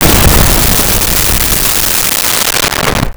Explosion 02
Explosion 02.wav